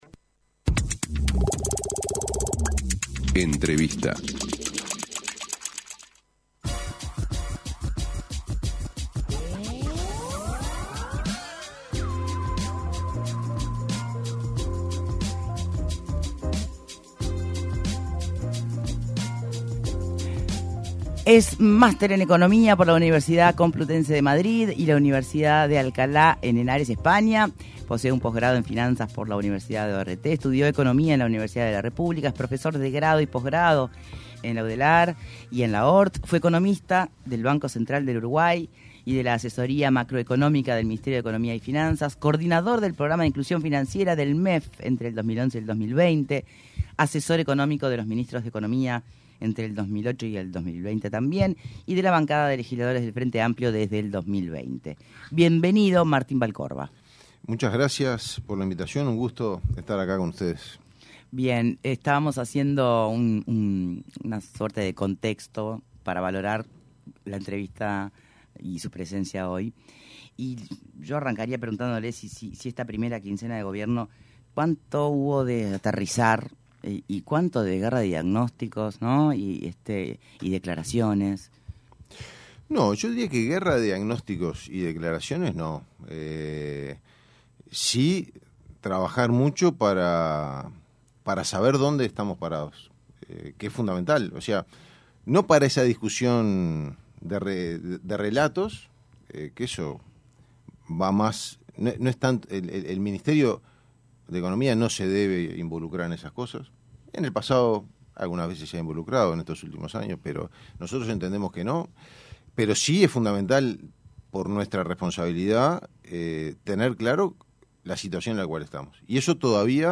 Escuche la entrevista completa aquí: El subsecretario del Ministerio de Economía y Finanzas, Martín Vallcorba, se refirió en entrevista con Punto de Encuentro, a la situación financiera que recibieron del anterior gobierno.